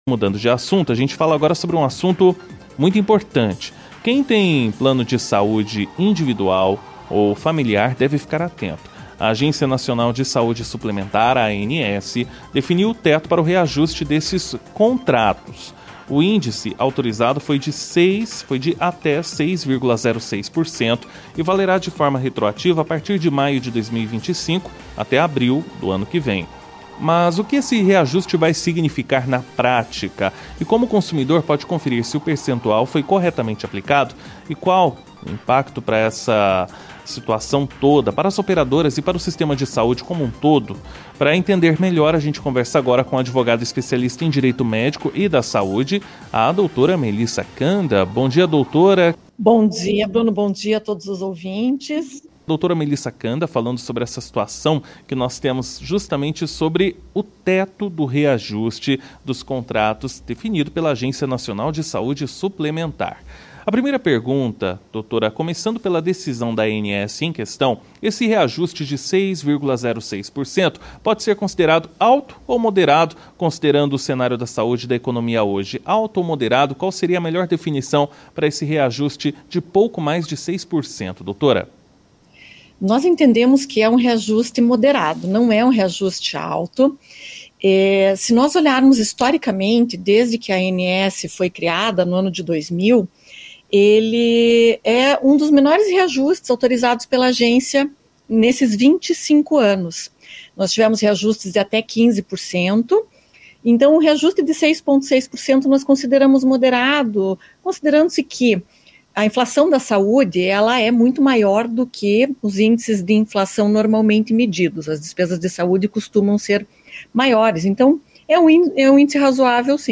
A advogada especialista em Direito Médico e da Saúde